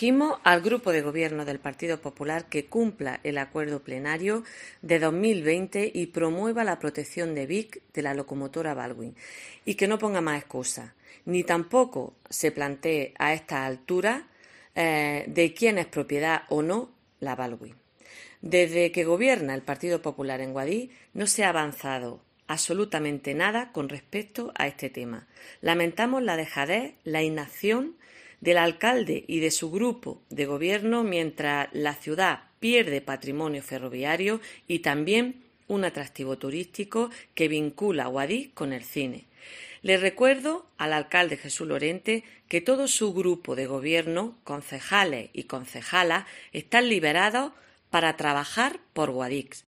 Belén Porcel, portavoz del PSOE en el Ayuntamiento de Guadix